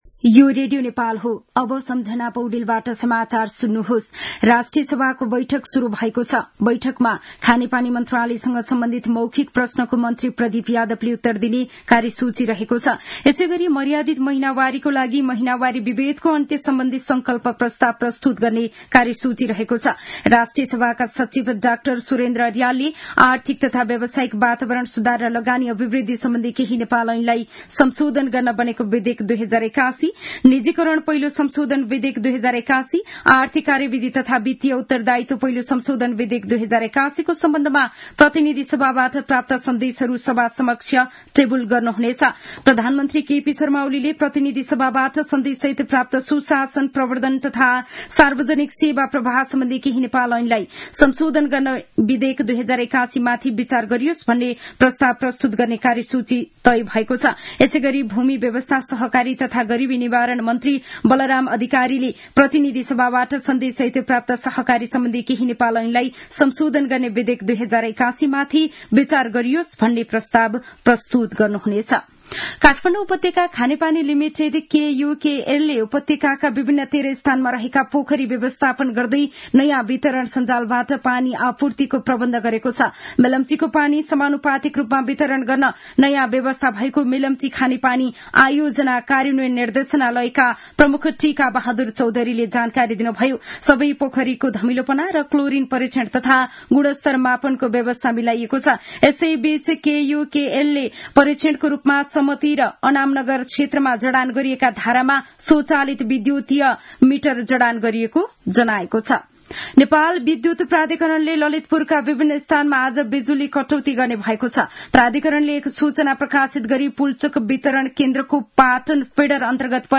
मध्यान्ह १२ बजेको नेपाली समाचार : ८ चैत , २०८१